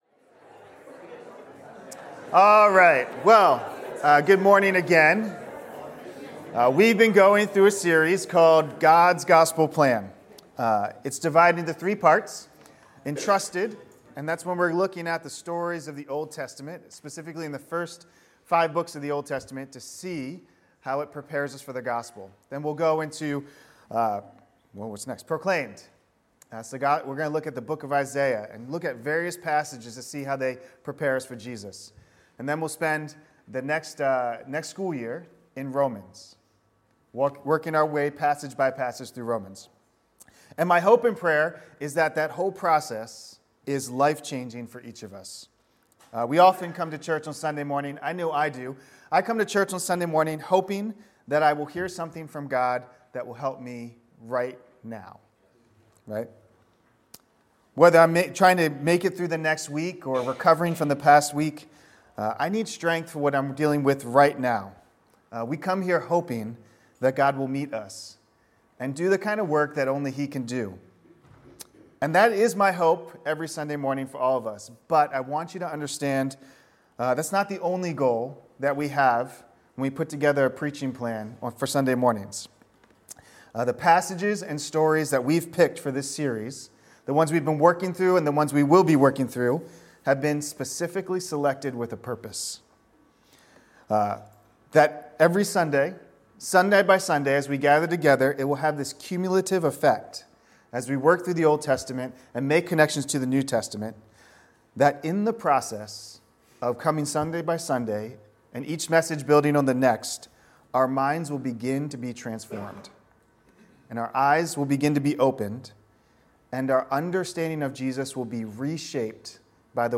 Entrusted-Foretold-Proclaimed Service Type: Celebration & Growth « The Glory of God in the Face of Jesus